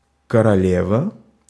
Ääntäminen
Synonyymit (arkikielessä) ферзь Ääntäminen Tuntematon aksentti: IPA: /kərɐˈlʲevə/ Haettu sana löytyi näillä lähdekielillä: venäjä Käännöksiä ei löytynyt valitulle kohdekielelle.